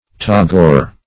tagore - definition of tagore - synonyms, pronunciation, spelling from Free Dictionary